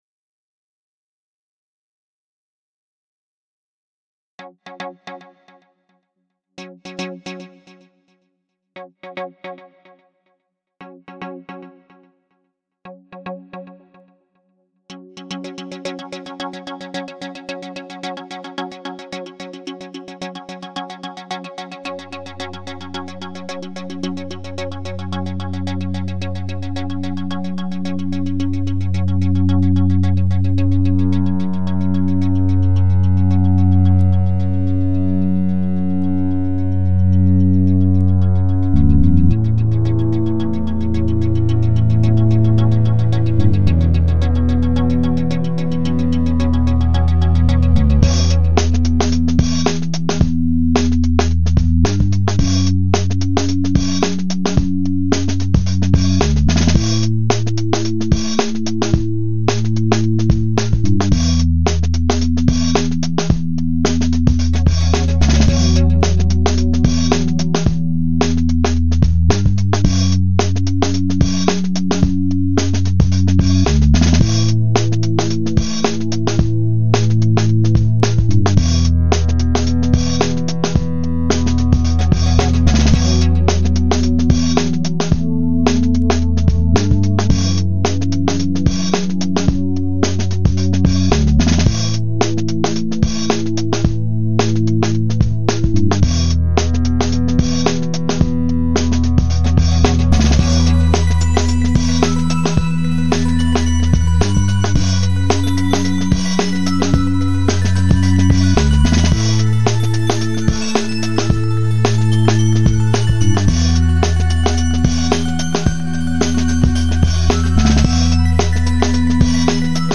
dance/electronic
Drum & bass
Ambient